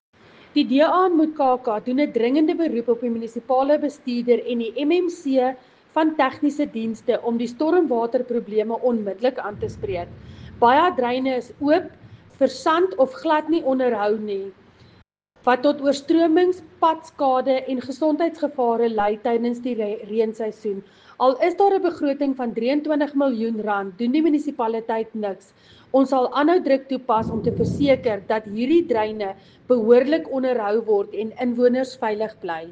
Afrikaans soundbites by Cllr Linda Louwrens and Sesotho soundbite by Cllr Kabelo Moreeng.